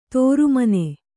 ♪ tōru mane